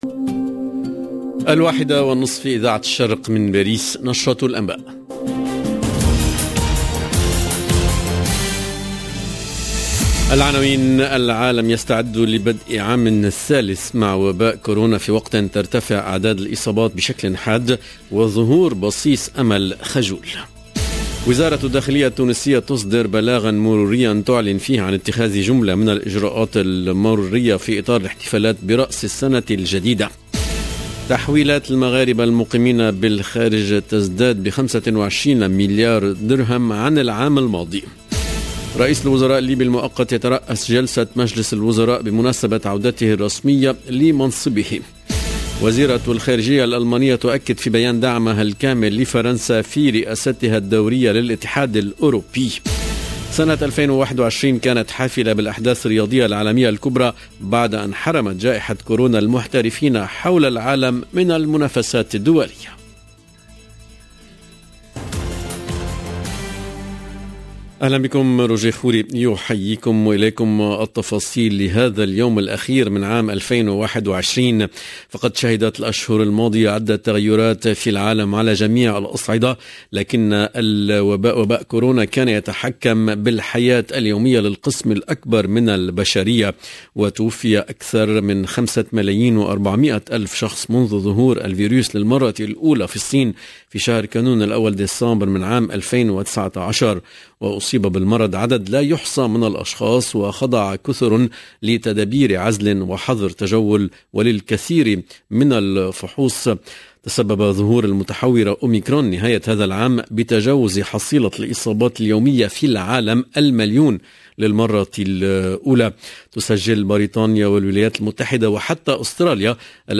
LE JOURNAL EN LANGUE ARABE DE 13h30 DU 31/12/21